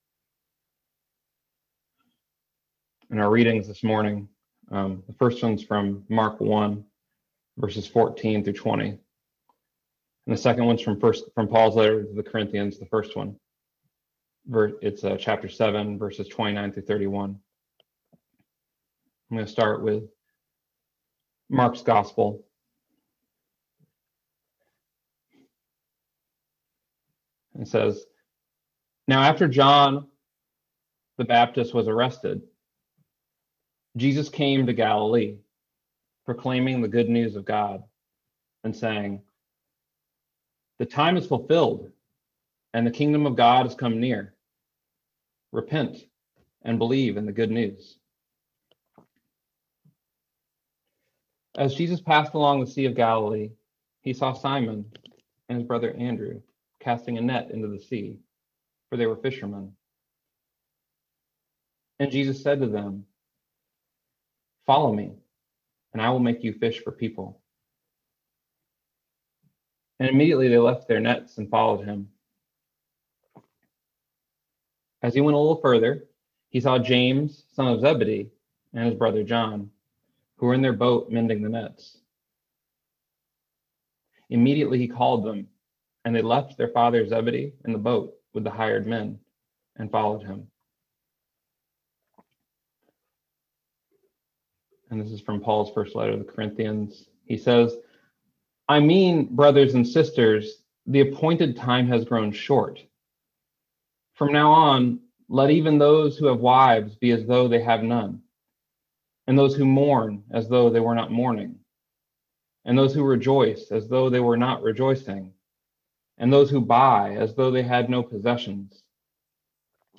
Listen to the most recent message from Sunday worship at Berkeley Friends Church, “The Time Is Fulfilled.”